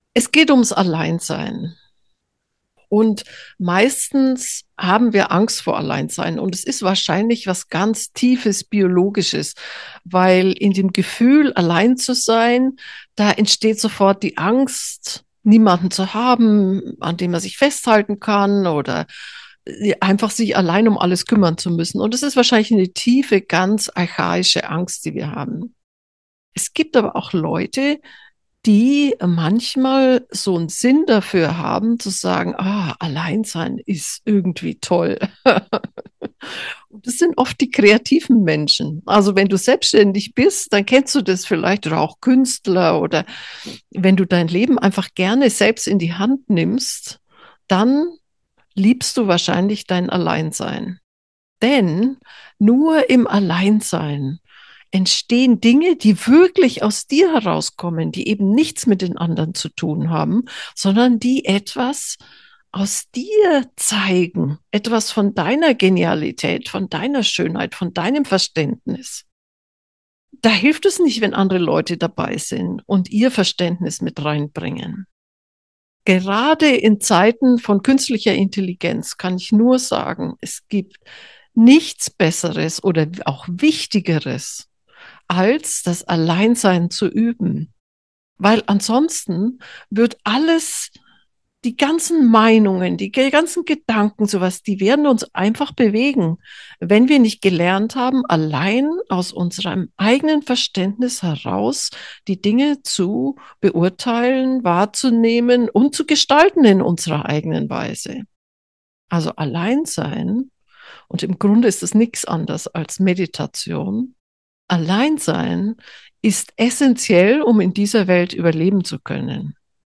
Geführte Meditationen